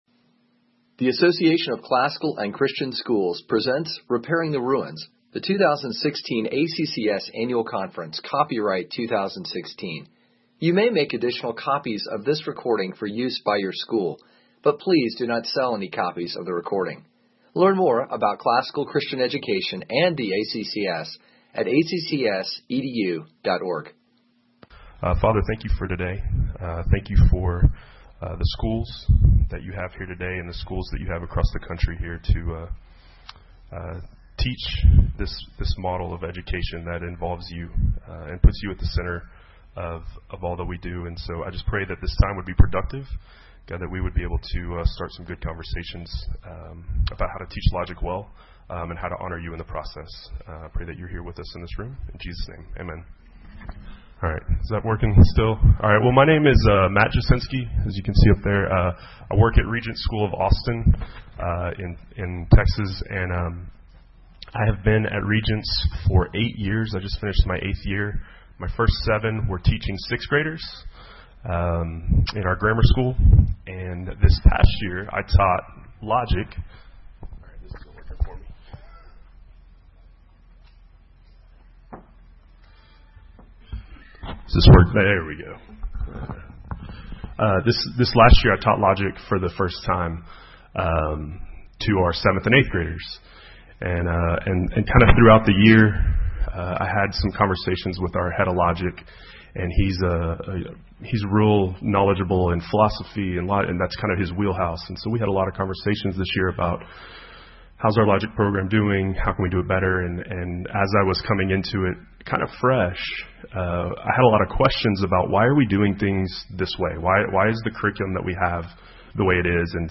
2016 Workshop Talk | 0:52:36 | 7-12, Logic